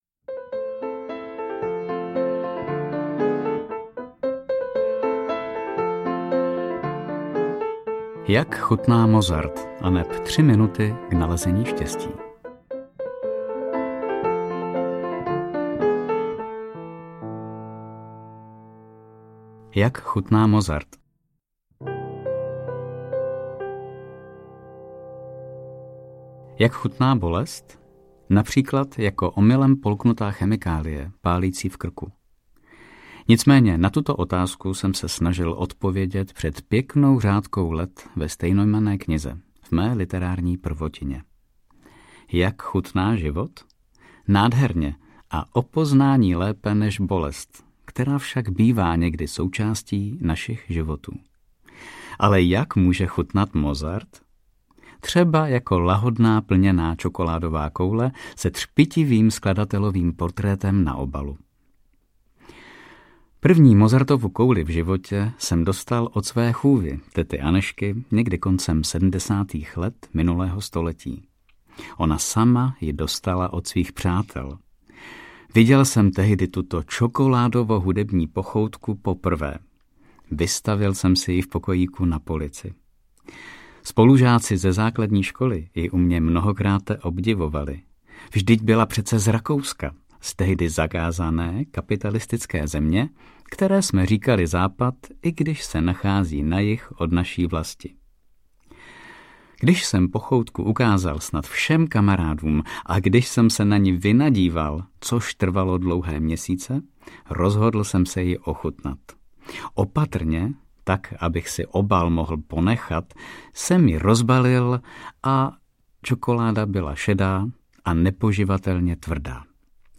Audio knihaJak chutná Mozart
Ukázka z knihy